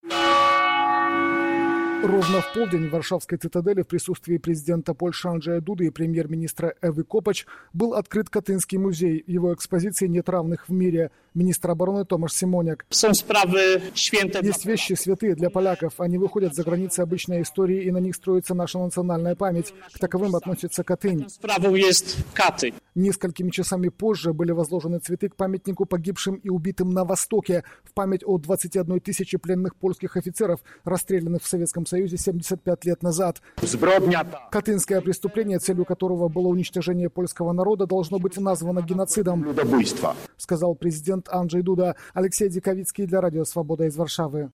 by Радио Свобода